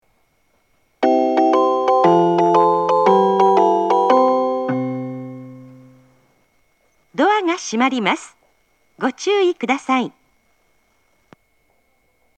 発車メロディー
一度扱えばフルコーラス鳴ります。
ユニペックス小型スピーカーは跨線橋側にあります。